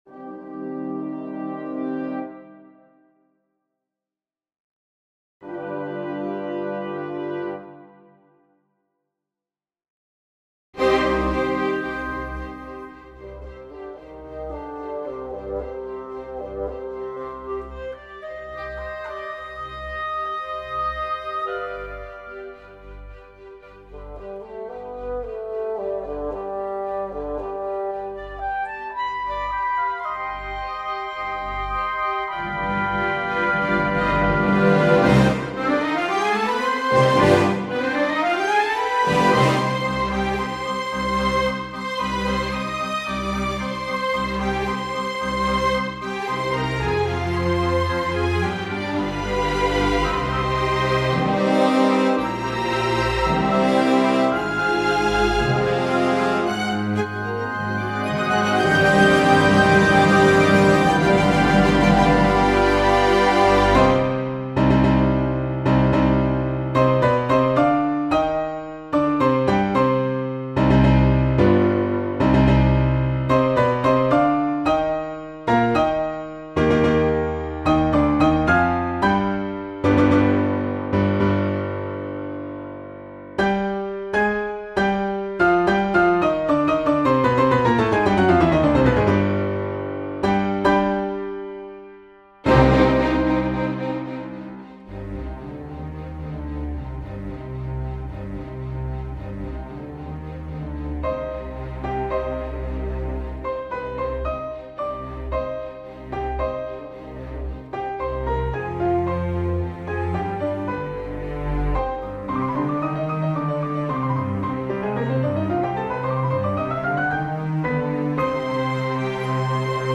WIP - First Piano Concerto (Feedback Wanted)